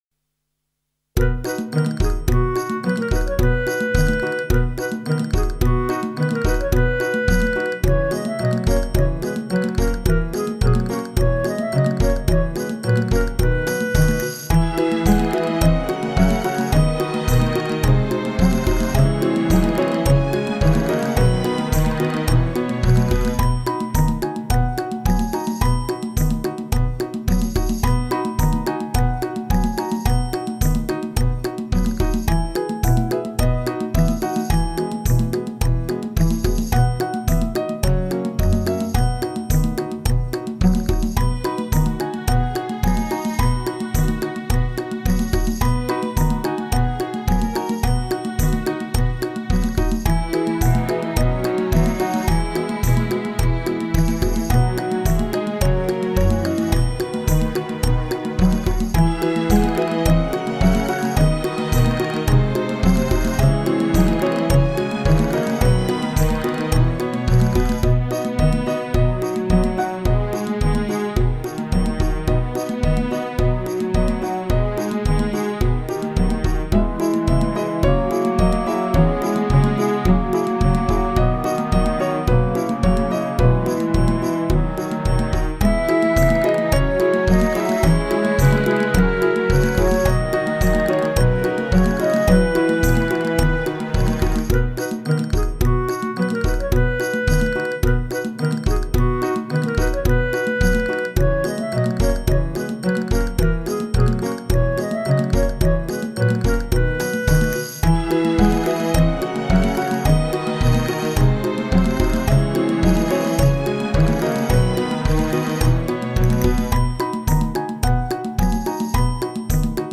گل کرده فصل جوانی بیکلام (جشن تکلف).mp3